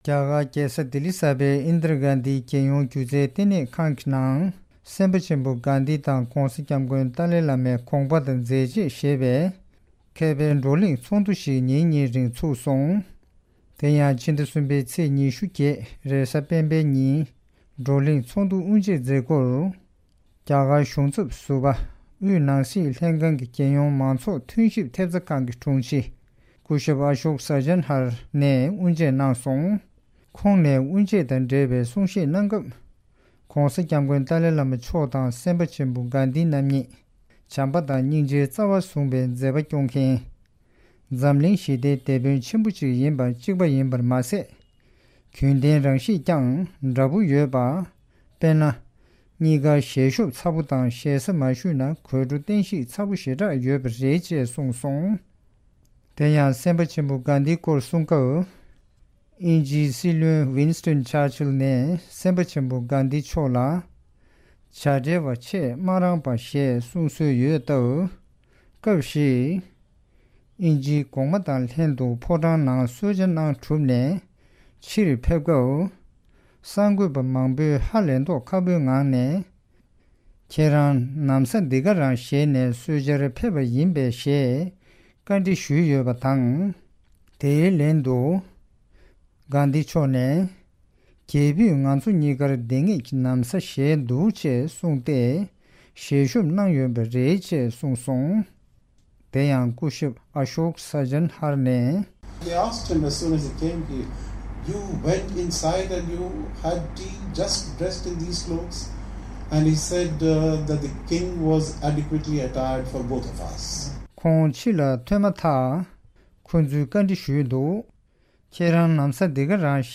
Seminar of Thoughts and Contributions of Gandhi and Dalai Lama
The Indira Gandhi National Centre for the Arts in collaboration with the Tibet House based in New Delhi organized a seminar on the contributions of Mahatma Gandhi and the Dalai Lama.